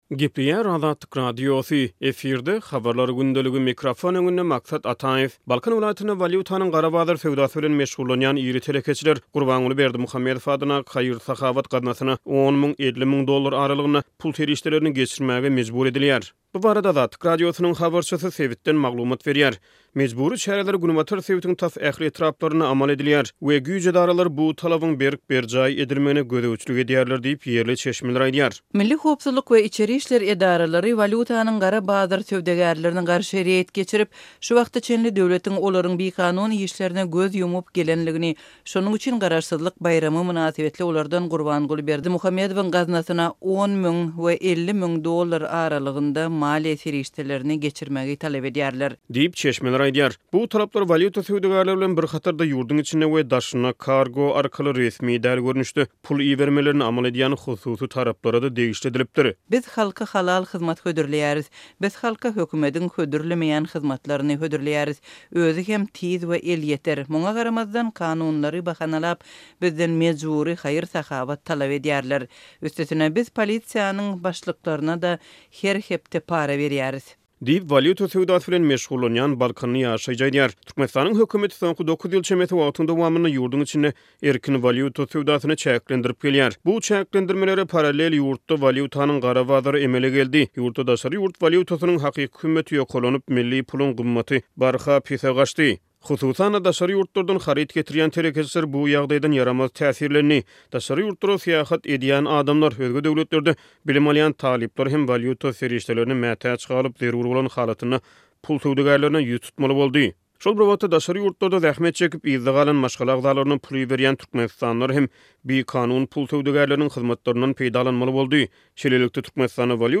Balkan welaýatynda walýutanyň gara bazar söwdasy bilen meşgullanýan iri 'telekeçiler' Gurbanguly Berdimuhamedow adyndaky haýyr-sahawat gaznasyna 10 müň – 50 müň dollar aralygynda pul serişdelerini geçirmäge mejbur edilýär. Bu barada Azatlyk Radiosynyň habarçysy sebitden maglumat berýär.